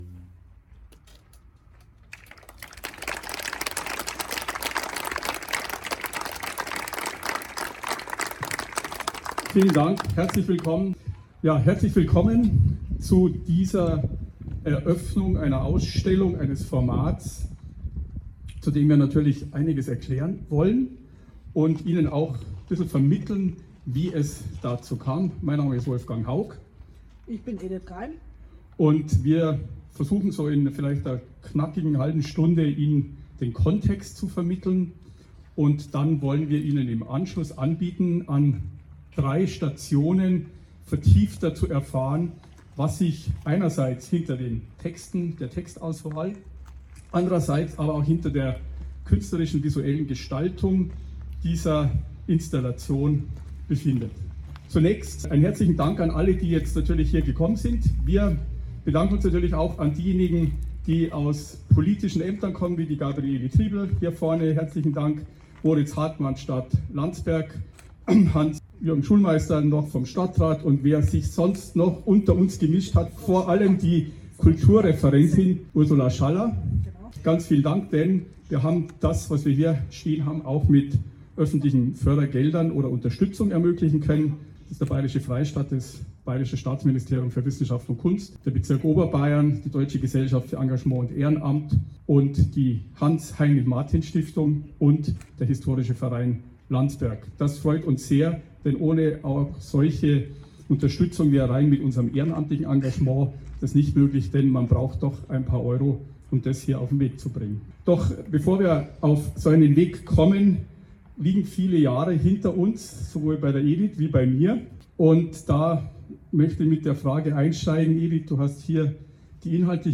TONAUFNAHME DER EINFÜHRUNG 6.9.2024 TRANSKRIPTION DER EINFÜHRUNG als PDF Informationen